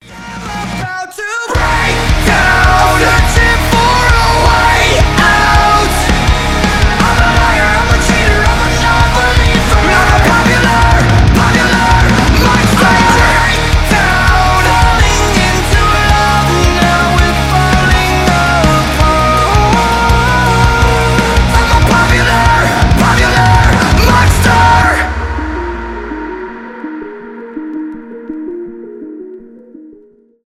мощные
metalcore
металкор